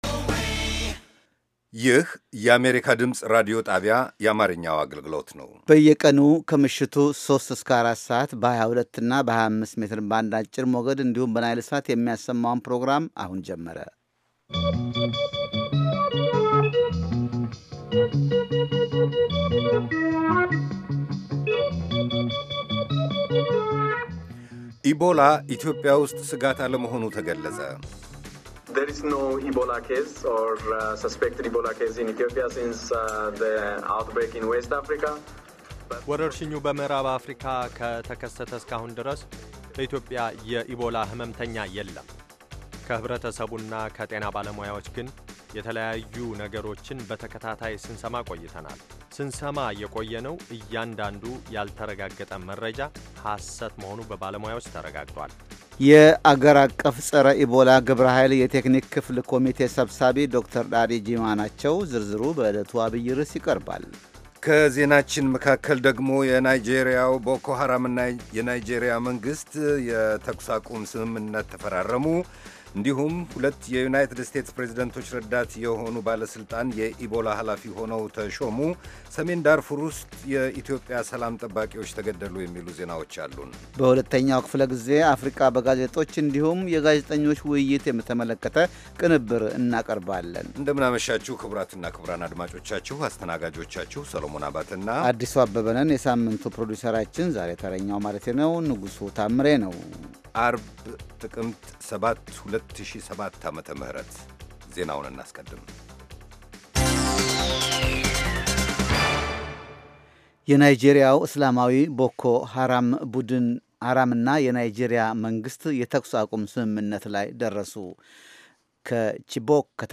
ዘወትር ከምሽቱ ሦስት ሰዓት ላይ ኢትዮጵያና ኤርትራ ውስጥ ለሚገኙ አድማጮች በአማርኛ የሚተላለፉ ዜናዎች፣ ቃለመጠይቆችና ሌሎችም ትኩስ ዘገባዎች፤ እንዲሁም በባሕል፣ በጤና፣ በሴቶች፣ በቤተሰብና በወጣቶች፣ በፖለቲካ፣ በግብርና፣ በንግድ፣ በተፈጥሮ አካባቢ፣ በሣይንስ፣ በቴክኖሎጂ፣ በስፖርት፣ በሌሎችም አካባቢያዊና የመላ አፍሪካ ጉዳዮች ላይ ያተኮሩ መደበኛ ዝግጅቶች የተካተቱባቸው የአንድ ሰዓት ዕለታዊ ሥርጭቶች